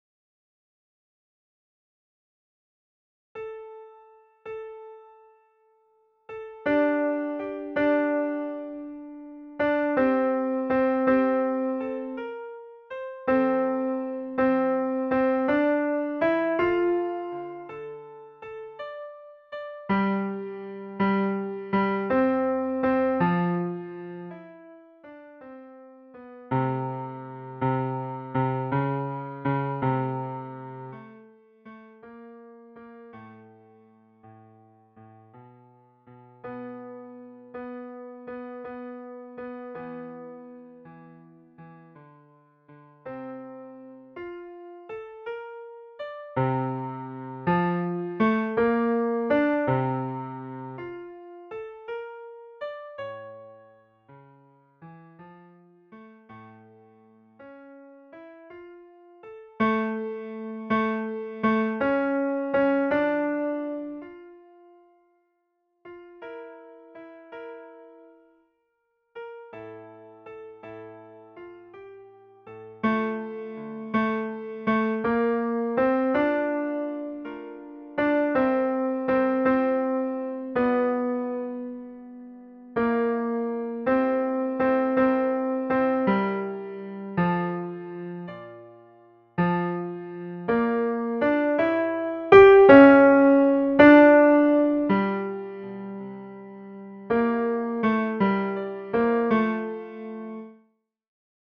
CHOEUR ET ECHO
Ténors
le_vent_dans_la_foret_tenors.mp3